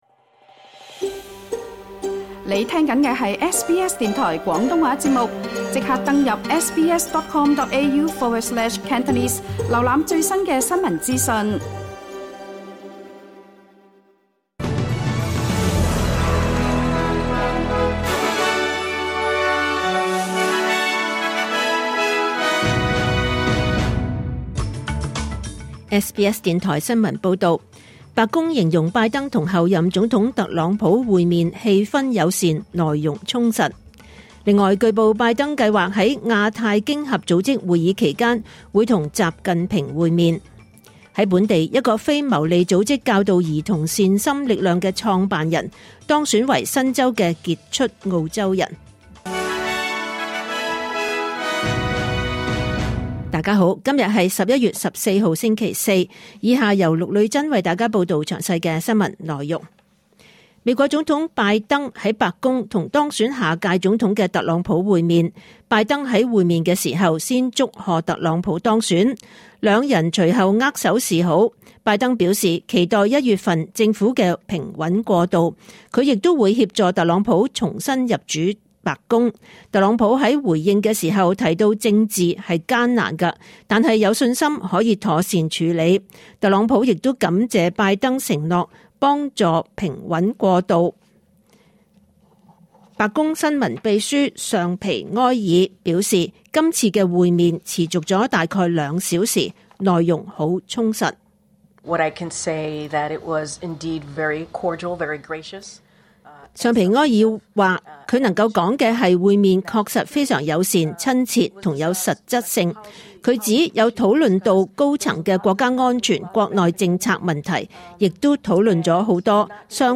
2024 年 11 月14 日 SBS 廣東話節目詳盡早晨新聞報道。